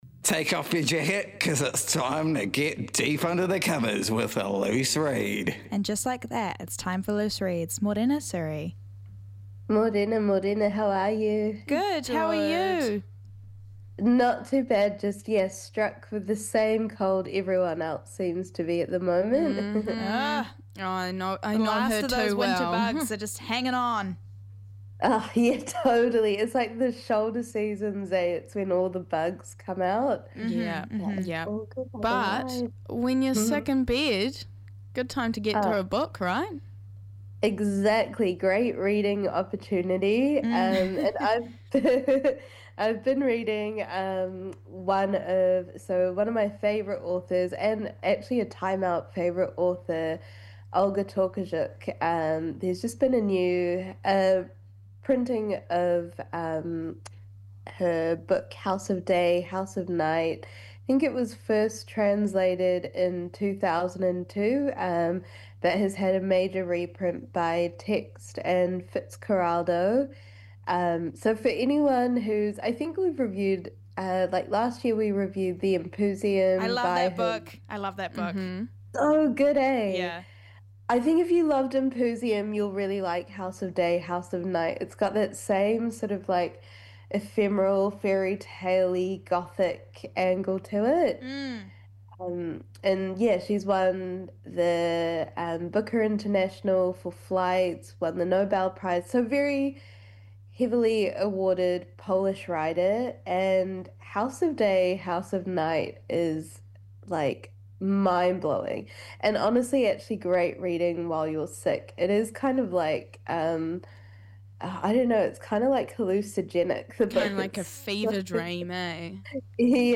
Listen back to feature interviews and performances from the 95bFM Breakfast Show.